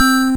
8-bit Happy Ding
Category 🎮 Gaming
8-bit 8bit arcade bleep chip chippy chiptune digital sound effect free sound royalty free Gaming